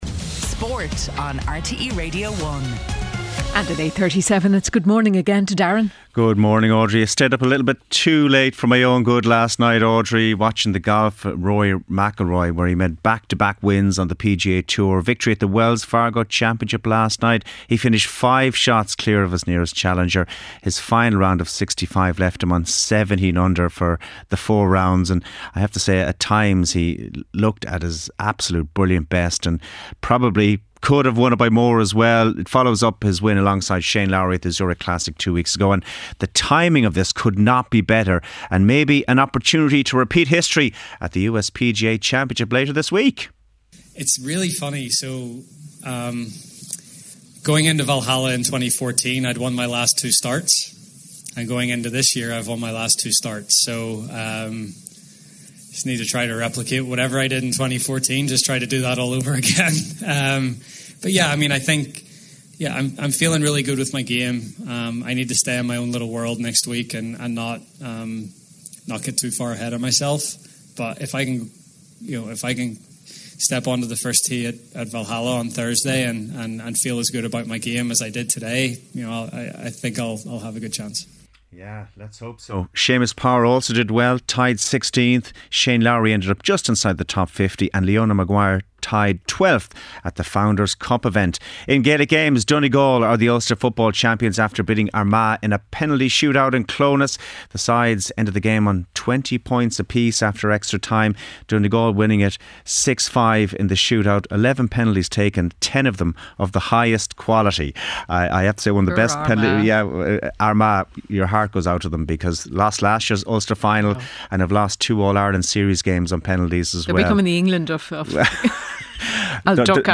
8:35am Sports News - 13.05.2024